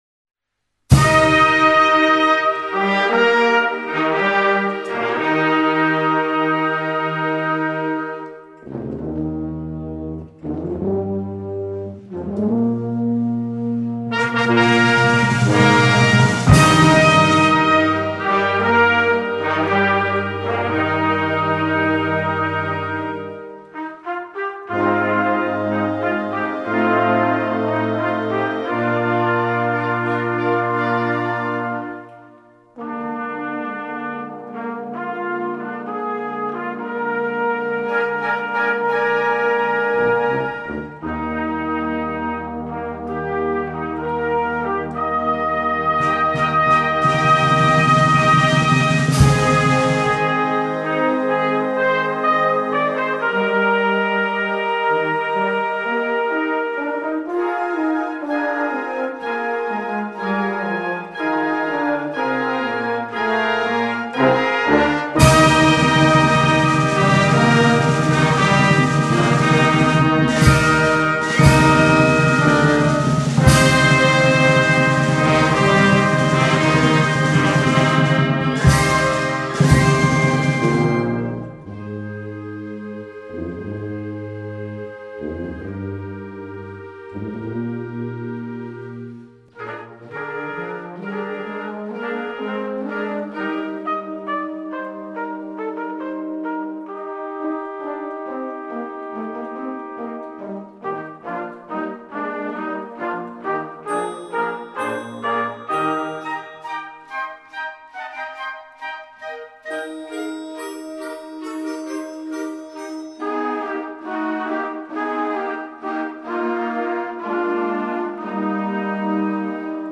Ouverture